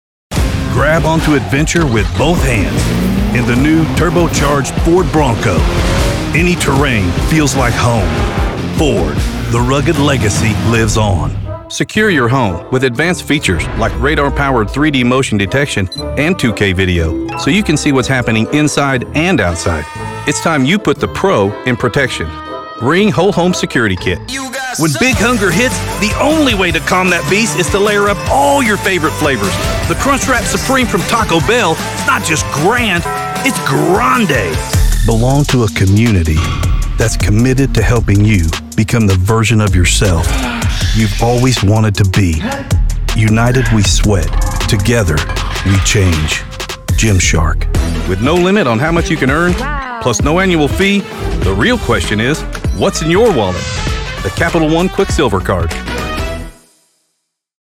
Commercial Demo reel
English - USA and Canada, English - Other